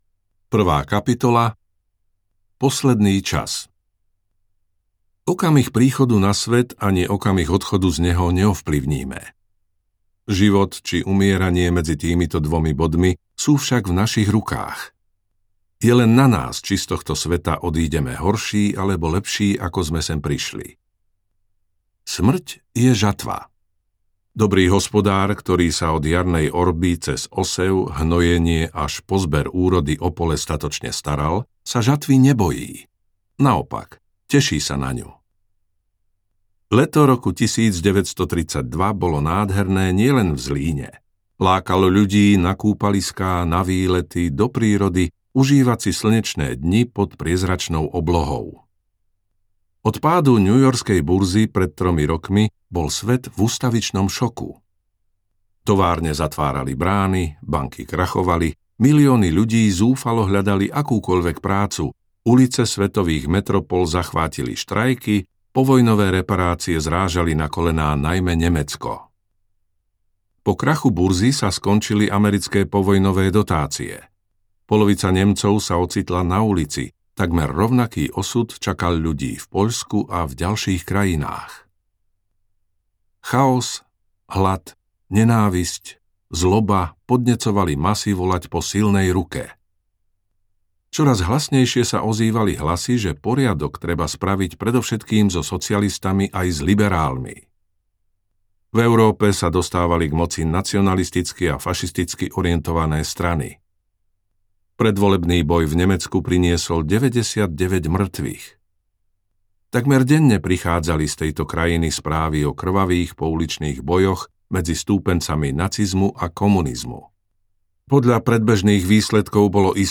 Audioknihu můžete také darovat